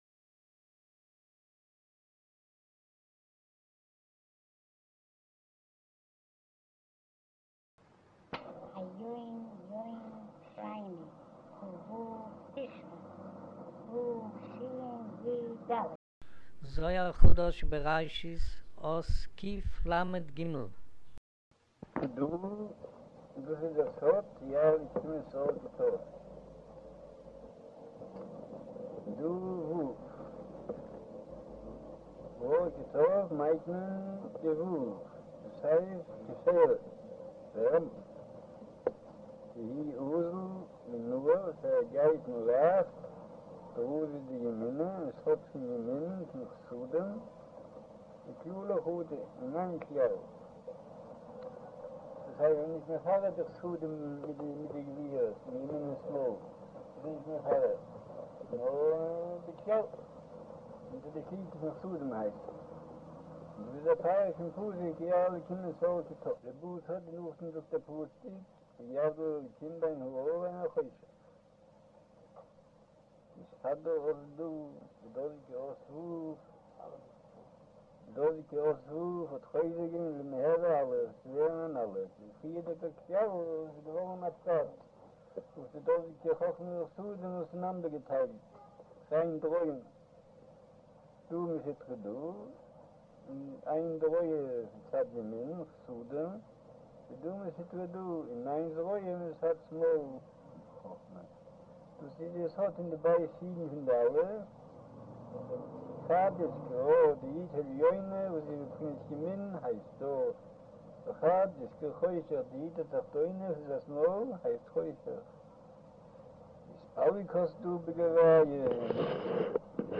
אודיו - שיעור מבעל הסולם זהר חדש בראשית אות קלג' - קלח'